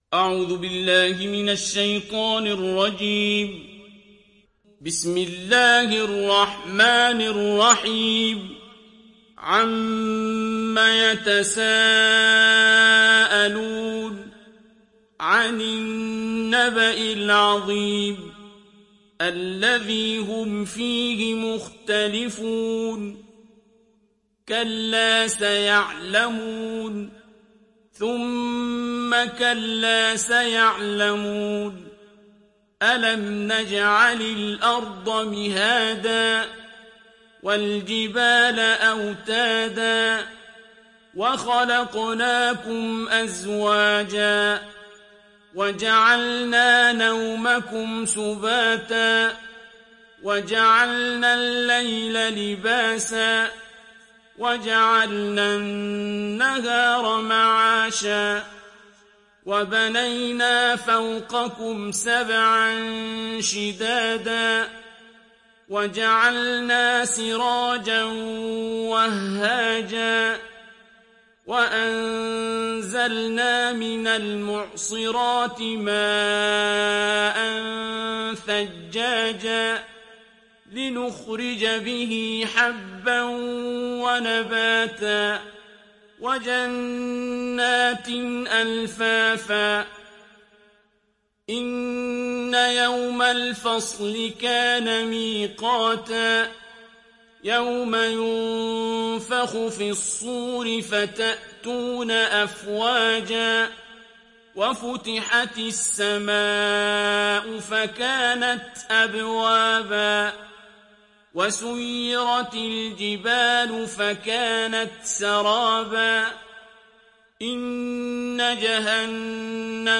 Surat An Naba mp3 Download Abdul Basit Abd Alsamad (Riwayat Hafs)
Surat An Naba Download mp3 Abdul Basit Abd Alsamad Riwayat Hafs dari Asim, Download Quran dan mendengarkan mp3 tautan langsung penuh